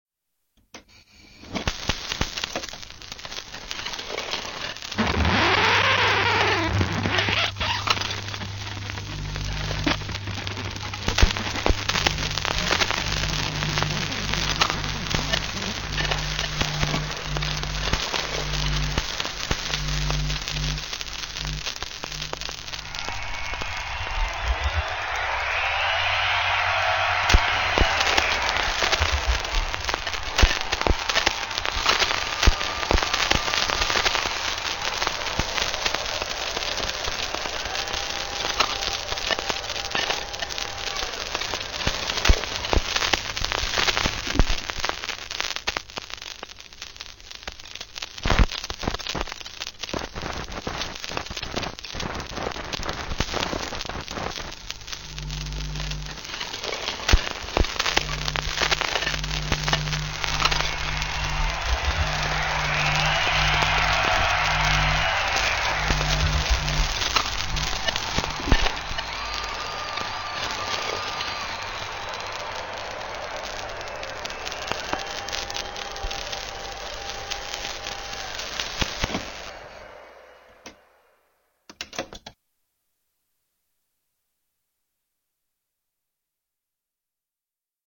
This interactive sound art work investigates the role and potential of the unintentional mistake — the deviation from the technical norm — in the process of recording and playing back acoustic signals. What can be heard, as the viewer generates multiple layers of sound from invisible interfaces hidden beneath the projection of the scratched and ruptured rotating surface, is a grinding, dirty, dusty static noise, which leaves little room for the desired acoustic signal itself.